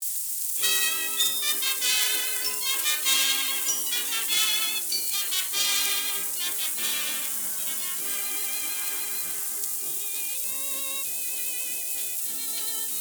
Here's the sound of the hiss dialed in, somewhere around the 8Khz mark
hissonly.mp3